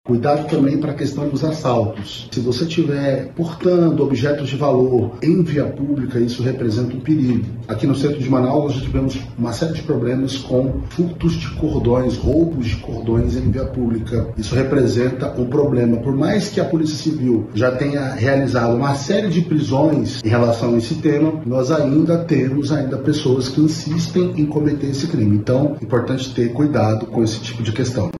O delegado adverte sobre algumas práticas criminosas que ainda são comuns, em especial, no Centro da cidade.